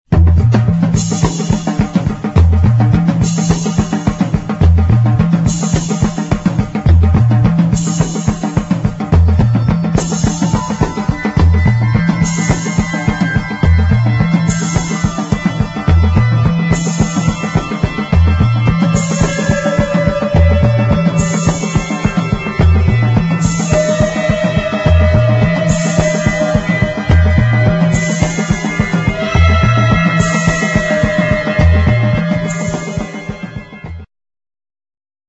nervous fast instr.